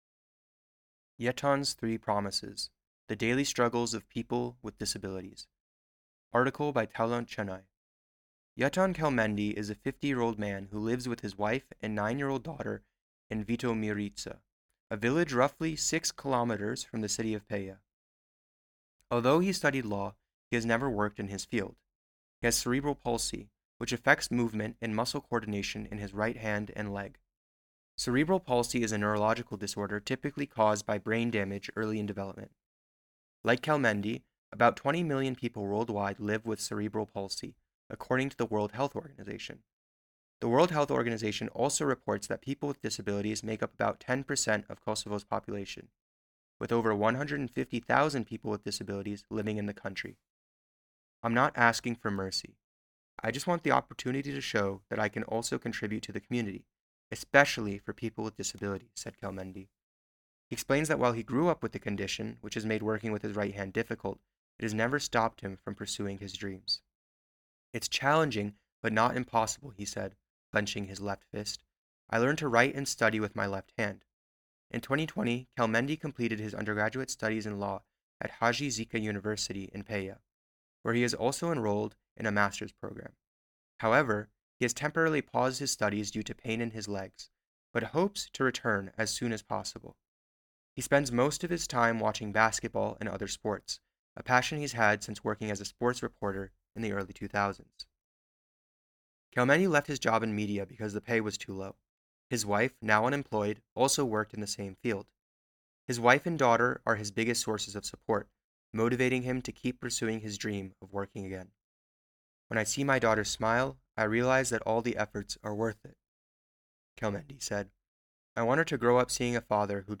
Below is a read-aloud version of the entire article.